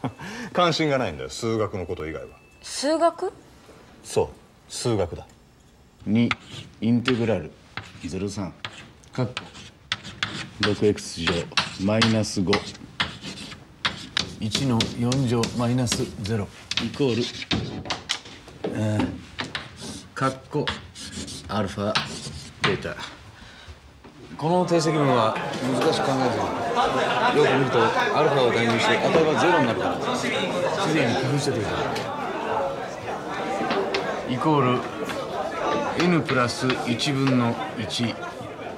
IMDb link The third scene a high school class with integrals.